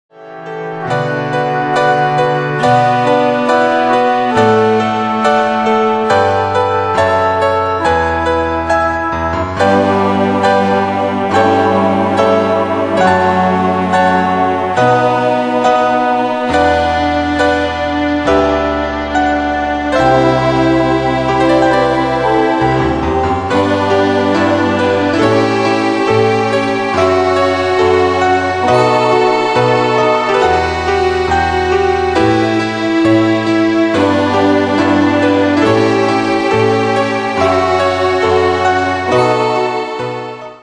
Demos Midi Audio
Style: PopFranco Année/Year: 1996 Tempo: 69 Durée/Time: 3.23
Danse/Dance: Ballade Cat Id.
Pro Backing Tracks